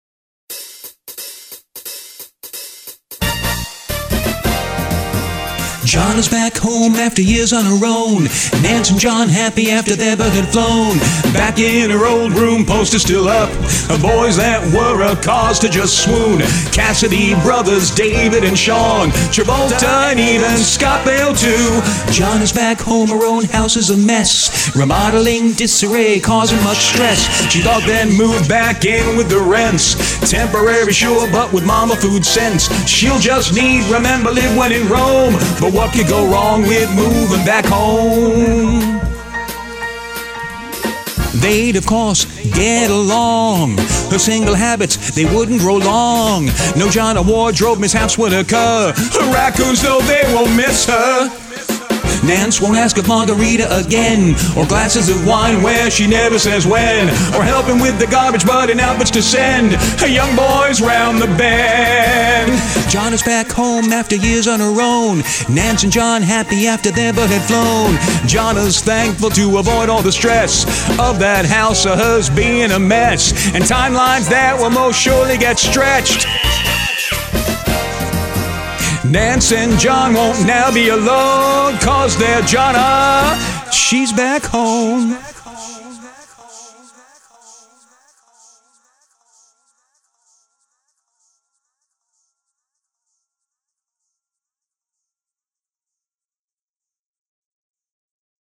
So we thought it would be funny for me to come up with a little intro/sting jingle type thing to lead into whatever her latest story of misadventure might be, a something I can do.
If I can’t keep up in the show I do have other things I can do, so I searched out “sitcom” this past Friday afternoon at our production music site, a wealth of good choices, but found the best of these sitcomy beds for just what was in my head. A few words later that afternoon, some ‘singing’ in my little studio after everyone had thankfully left for the weekend (other than time spent at the house with my furry girls it’s my favorite of moments) and then back home to build.